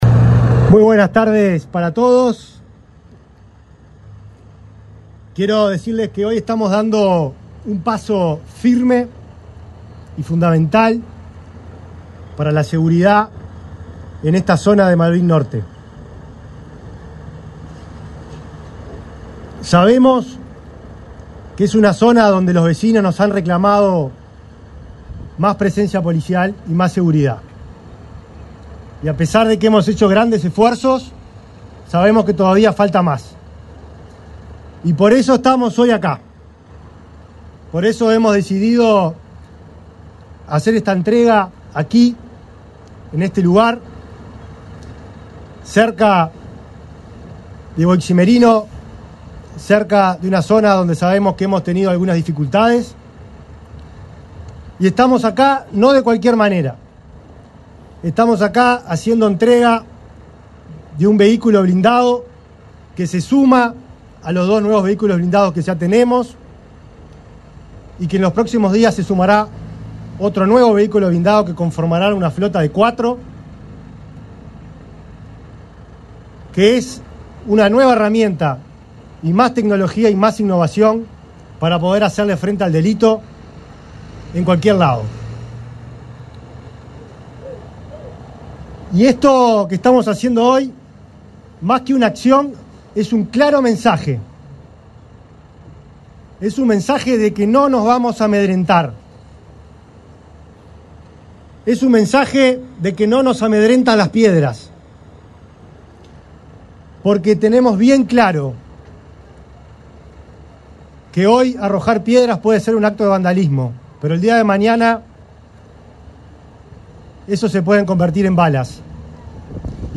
Palabras del ministro del Interior, Nicolás Martinelli